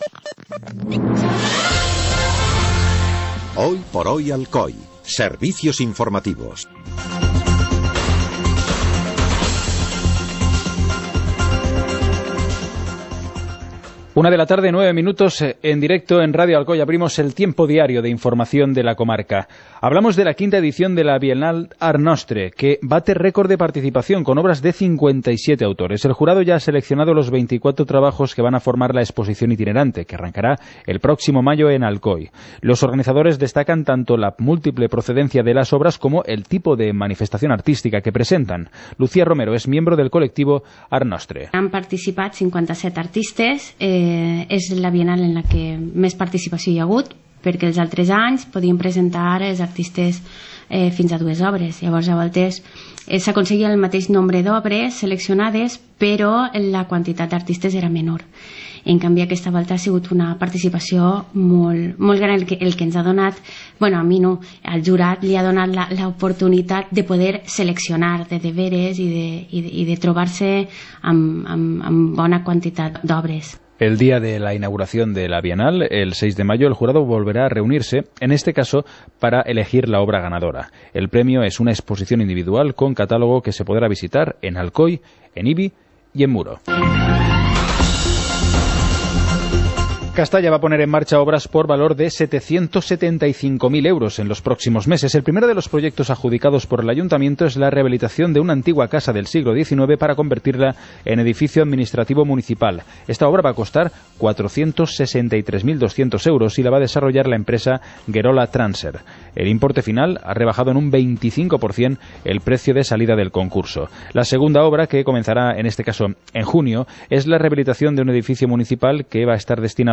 Informativo comarcal - miércoles, 29 de marzo de 2017